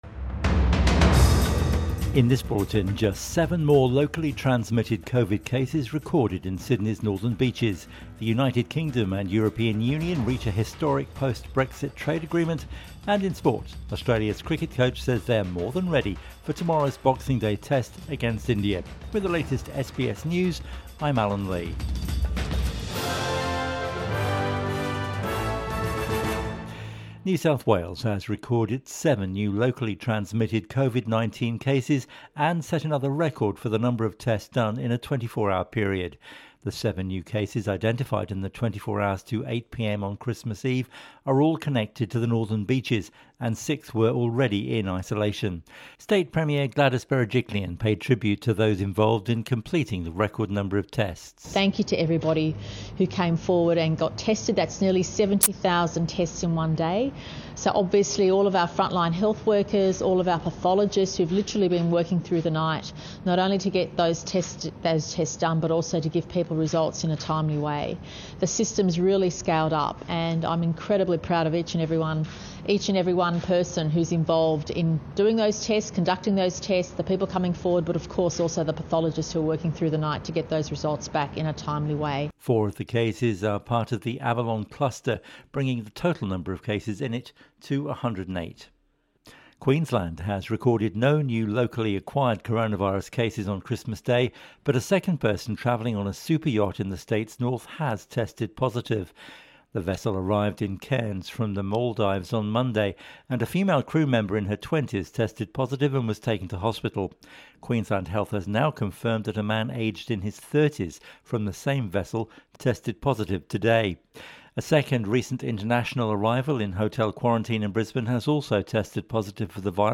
PM Bulletin 25 Dec 2020